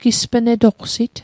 Pronunciation Guide: gis·pê·ne·dohk·sit